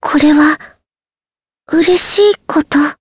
Jane-Vox_Victory_jp.wav